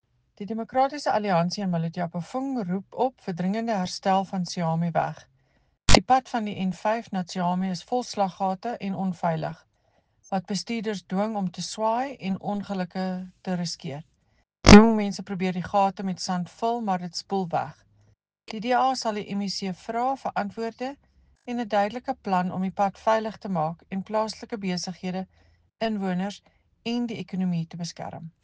Afrikaans soundbites by Cllr Eleanor Quinta and